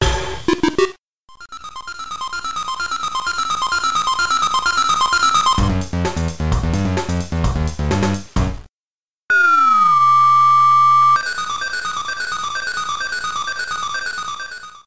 Fade out